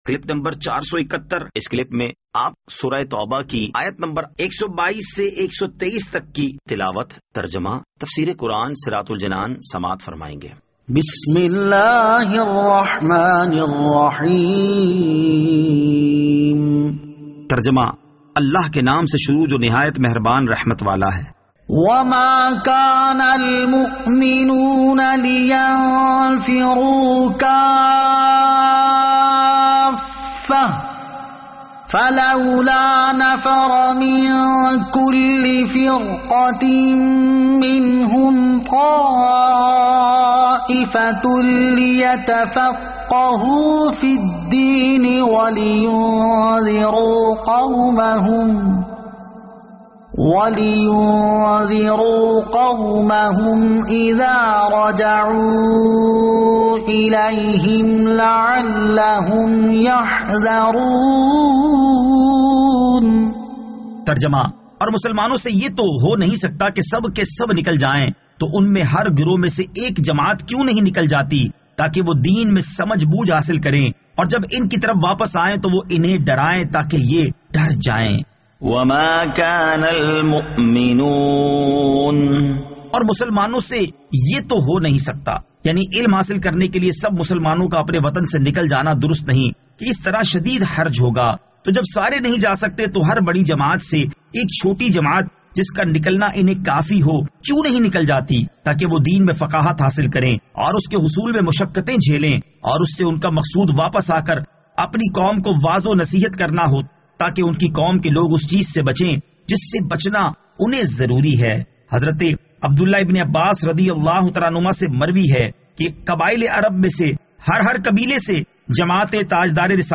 Surah At-Tawbah Ayat 122 To 123 Tilawat , Tarjama , Tafseer